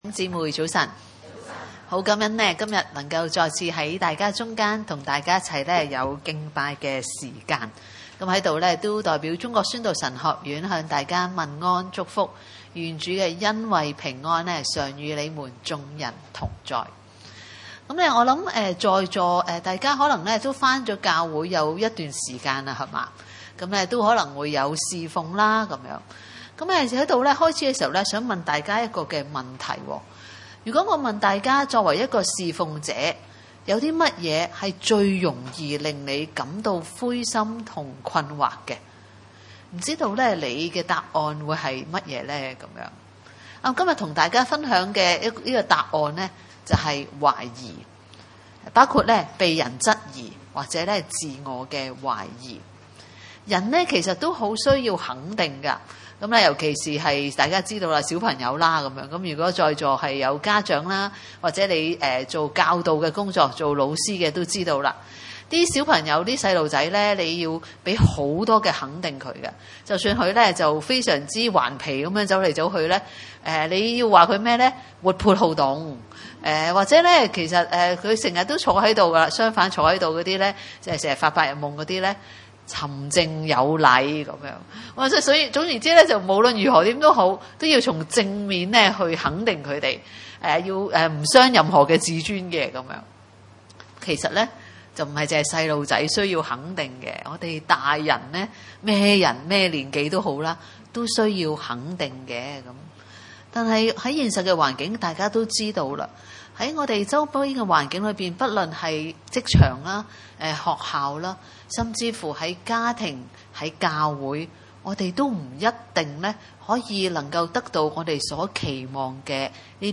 1-8（聖經新漢語譯本） 崇拜類別: 主日午堂崇拜 1 所以，弟兄們，我以神的憐憫勸你們，要把身體作為活祭獻上，這祭是聖潔的，是神所喜歡的；這是你們理當獻上的敬拜。